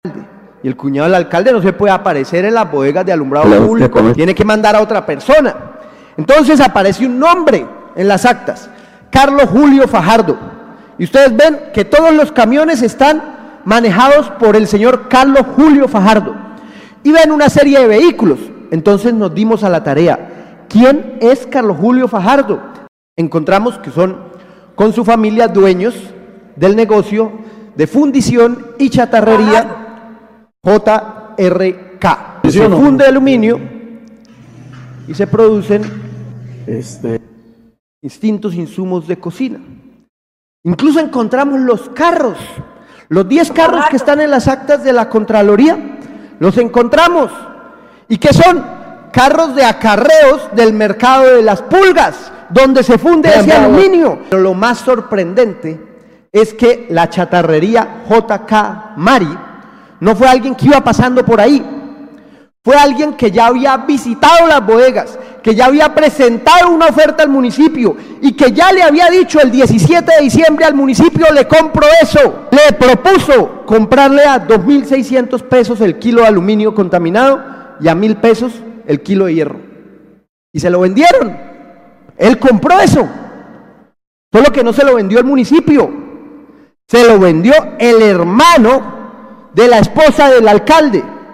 La pérdida de luminarias y chatarra del alumbrado público de la ciudad fue tema central en el más reciente debate de control político en el Concejo de Bucaramanga.
Carlos Parra, concejal de Bucaramanga